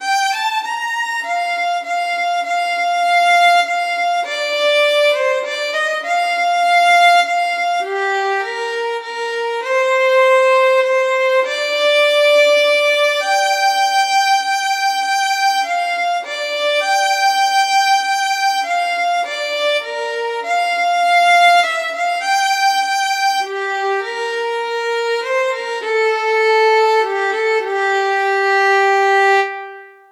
23_lovessolace_30319_st15_ll.5_8_damaskrose_fiddle.mp3 (943.67 KB)
Audio fiddle of transcribed recording of stanza 15, lines 5–8, of “Loues Solace”; sung to “Damask Rose”